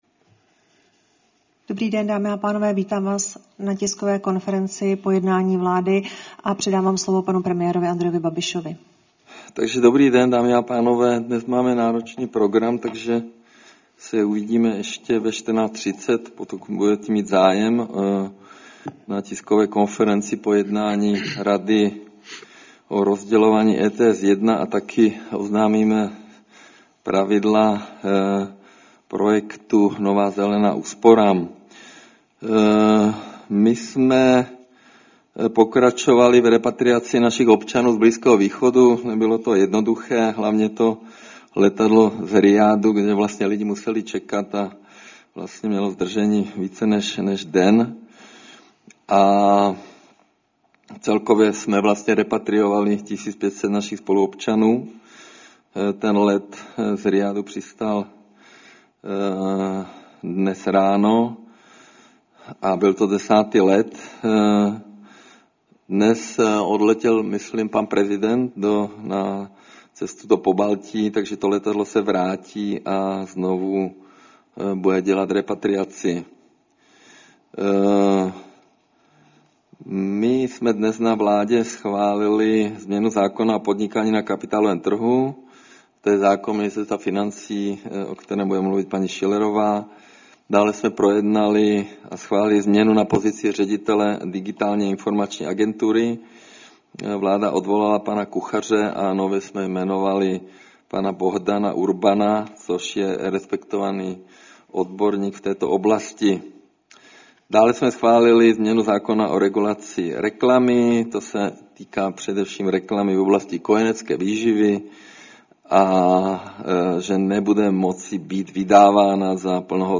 Tisková konference po jednání vlády, 9. března 2026